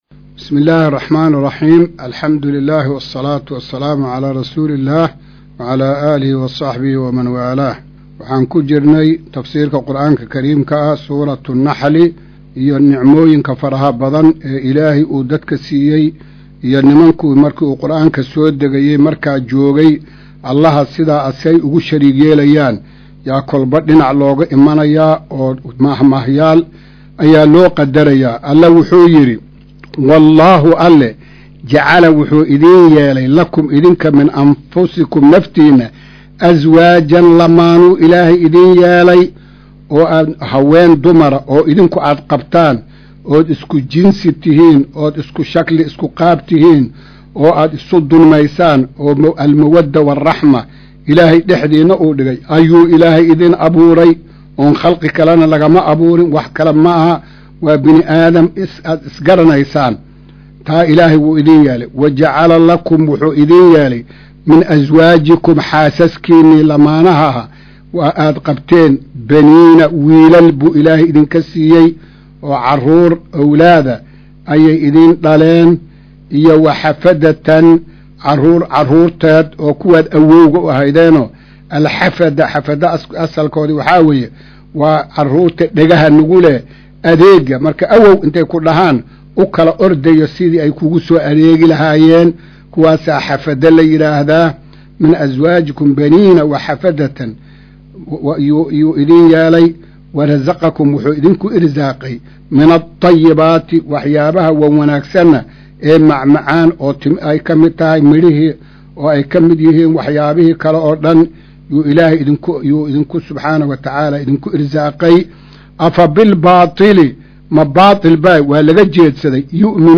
Maqal:- Casharka Tafsiirka Qur’aanka Idaacadda Himilo “Darsiga 134aad”